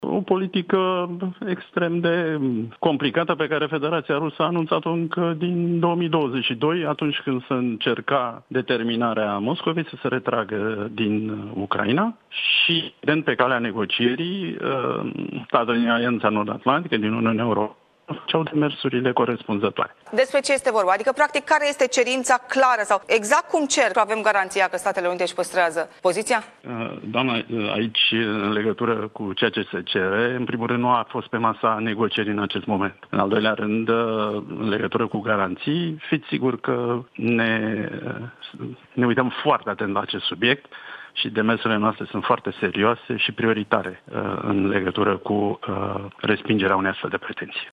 Într-o intervenție telefonică, joi dimineață, la Digi 24, Cristian Diaconescu a susținut că subiectul de tipul împărțirii influenței în Europa de tip Ialta nu este pe actuala agendă a discuțiilor SUA – Rusia.